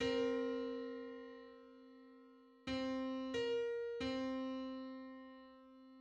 English: *Hundred-fifteenth harmonic on C = A ♯ +. Just: 115:64 = 1014.59 cents.
Public domain Public domain false false This media depicts a musical interval outside of a specific musical context.
Hundred-fifteenth_harmonic_on_C.mid.mp3